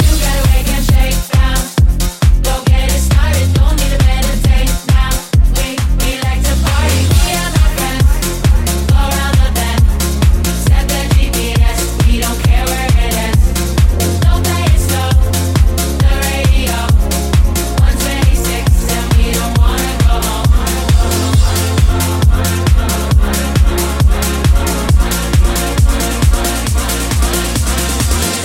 Genere: pop,deep,dance,disco,news